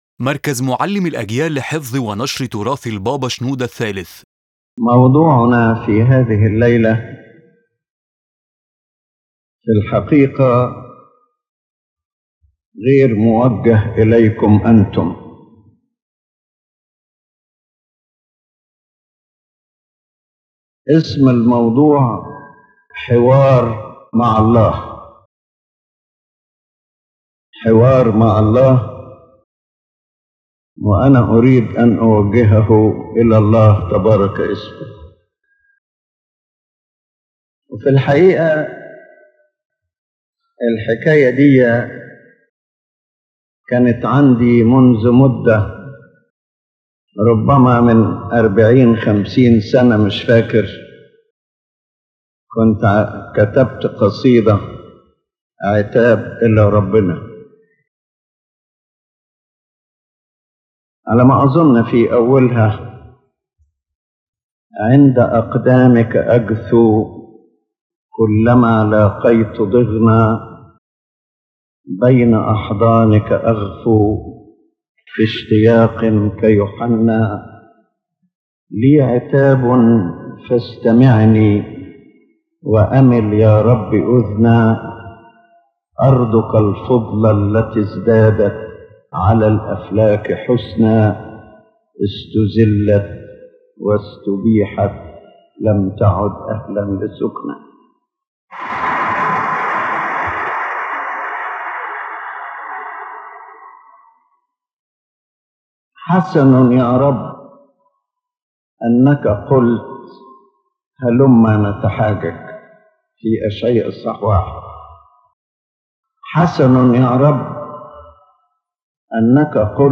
In this profound sermon, His Holiness presents a spiritual dialogue between man and God, filled with questions and reflections about divine justice, mercy, and salvation. The message expresses man’s longing to understand God’s ways and his dependence on divine grace for salvation.